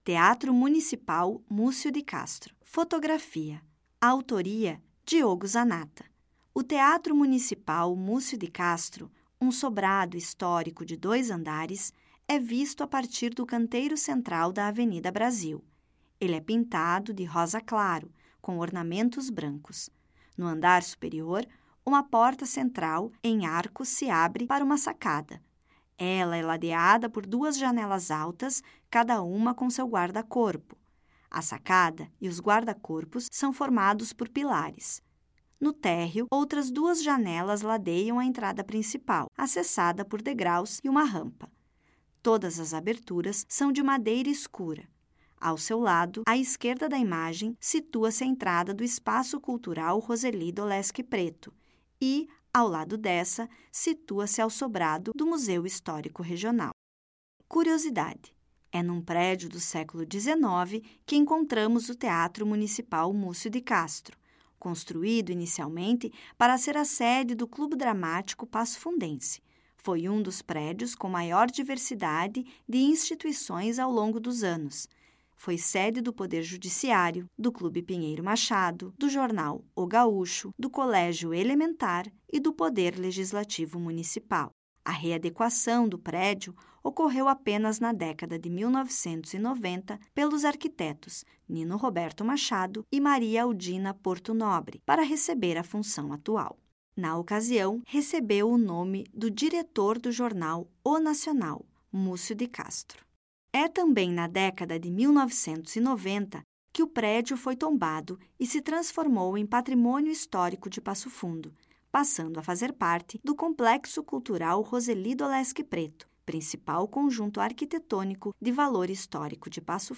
Audioguia
Audiodescrição